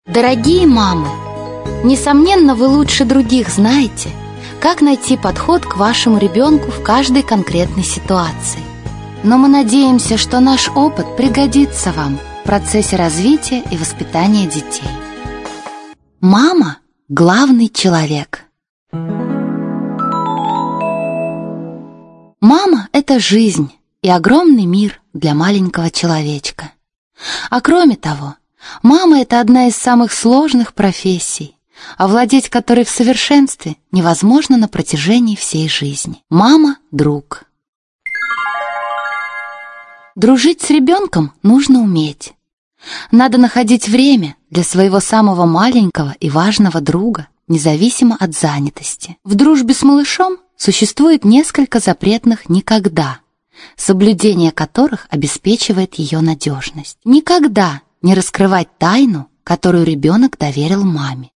Аудиокнига Гармоничное развитие ребенка от 3 до 6-х лет | Библиотека аудиокниг